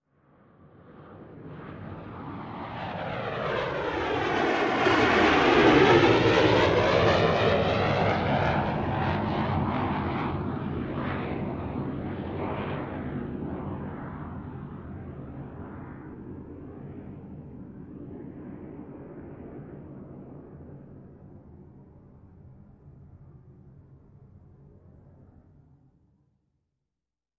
Jet Take Off & By, Large Commercial, ( 1 ), take Off, By, Long Away